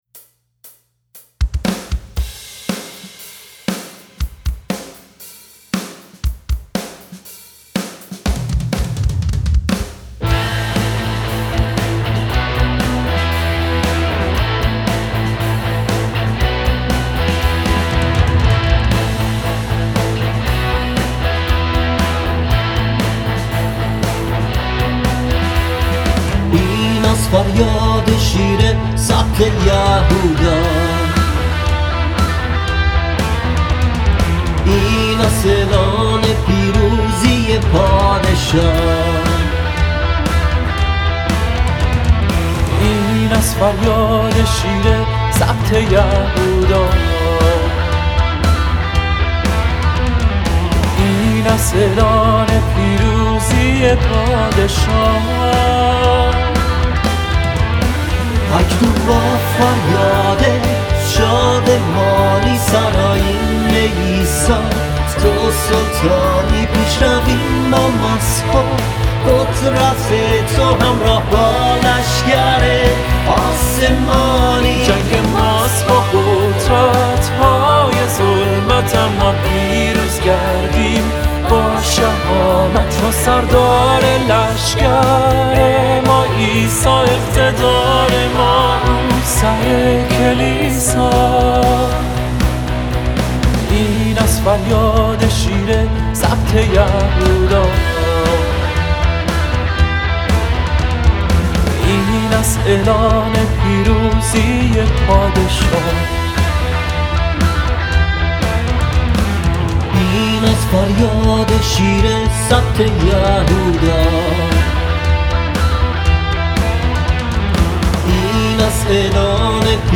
مینور
Minor